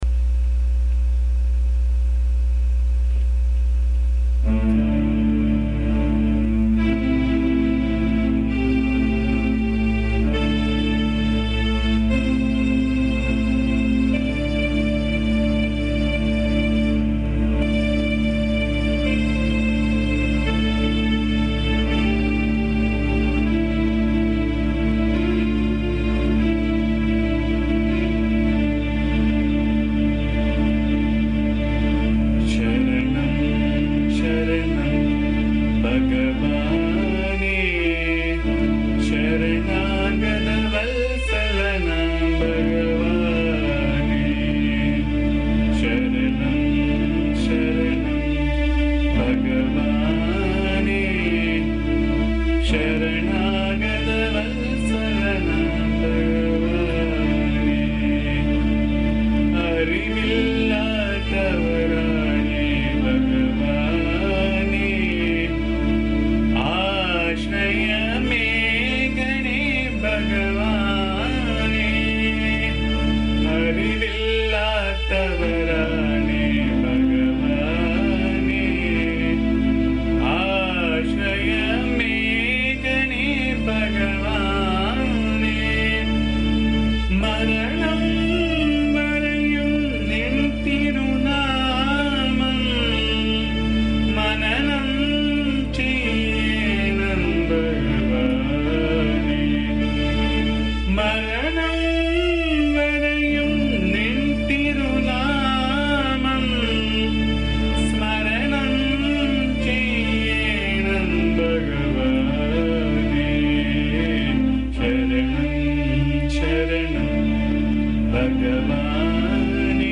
This is a very simple song about a devotee's state of surrender and how a devotee prays unto the Lord to remember him at all times (and thereby remember him at death). The song is set in Sree Ragam.
Please bear the noise, disturbance and awful chanting as am not a singer.
AMMA's bhajan song